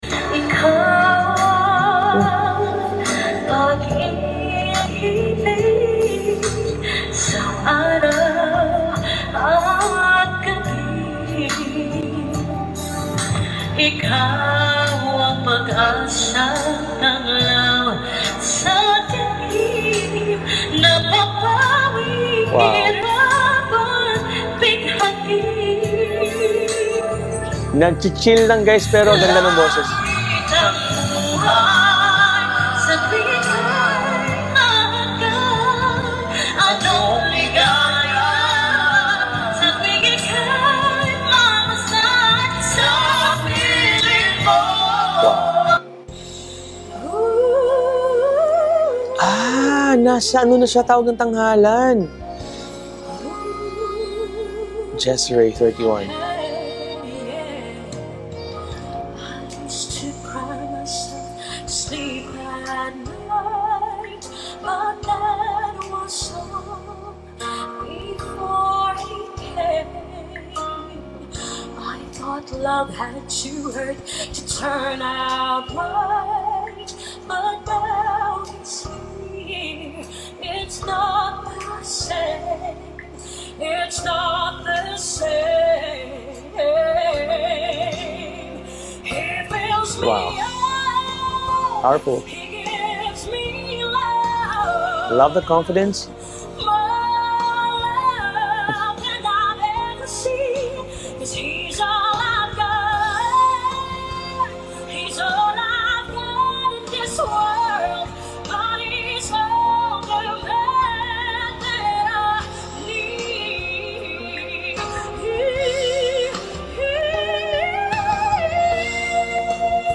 From a viral Mp3 Sound Effect Grabe no? From a viral video, ngayon nasa TNT stage na siya! Ang lupet ng boses with solid control, perfect timing, at ramdam yung chill vibe na biglang nagiging powerful!